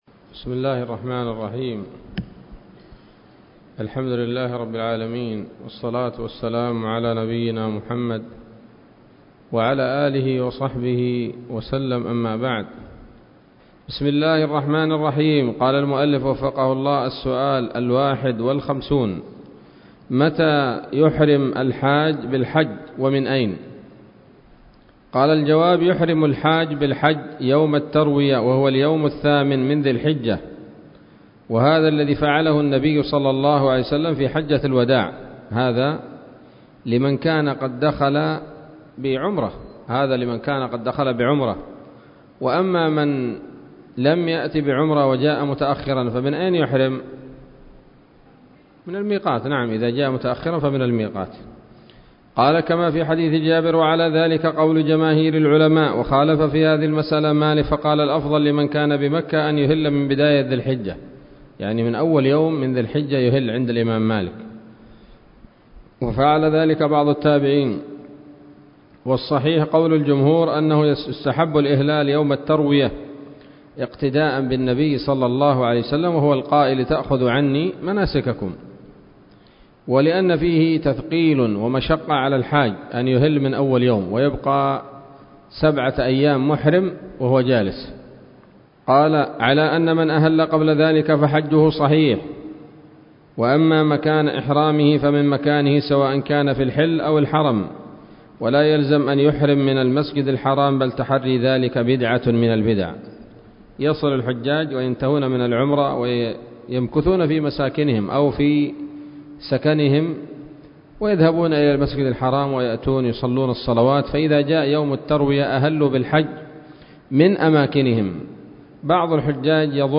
الدرس الثامن والعشرون من شرح القول الأنيق في حج بيت الله العتيق